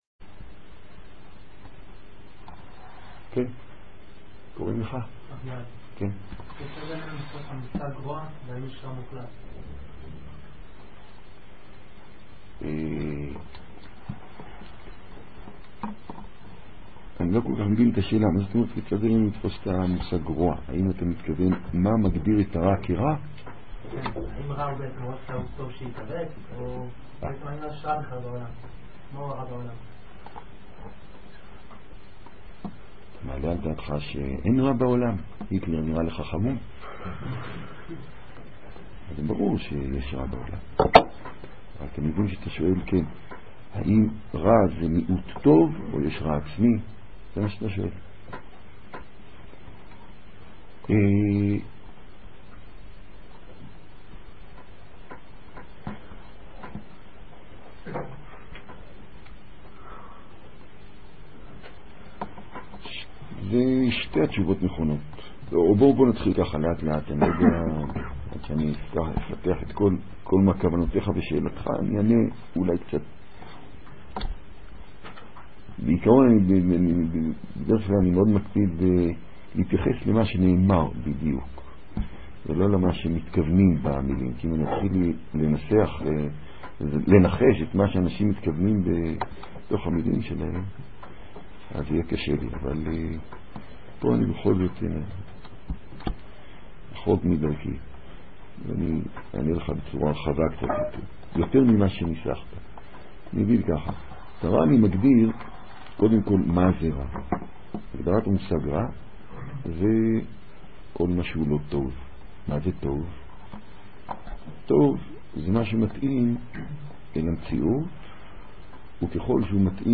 מתוך שו"ת. ניתן לשלוח שאלות בדוא"ל לרב